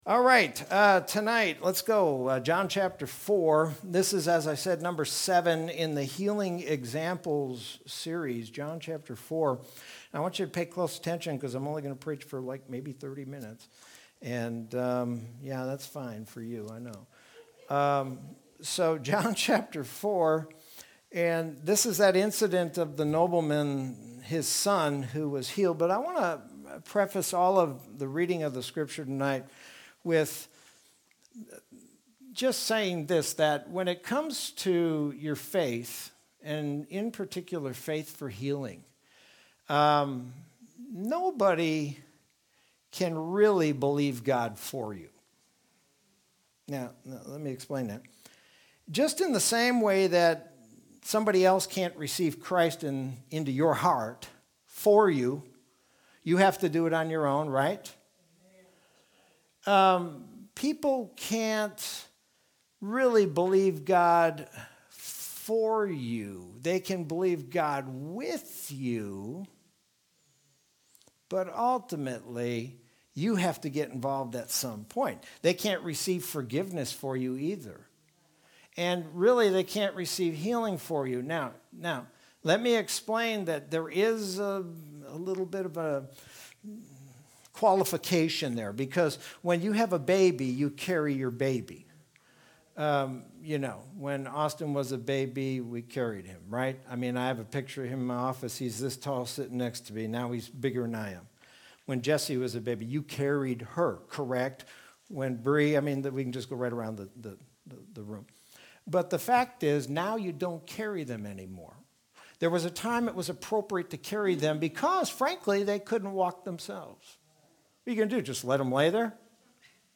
Sermon from Wednesday, March 3rd, 2021.